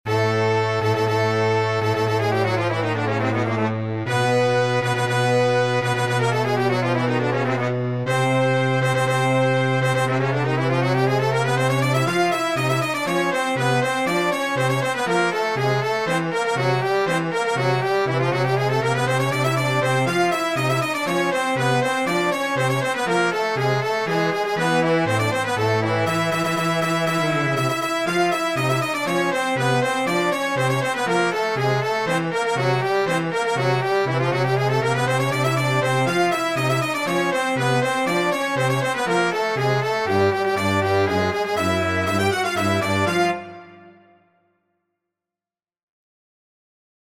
Classic "Circus Music" key F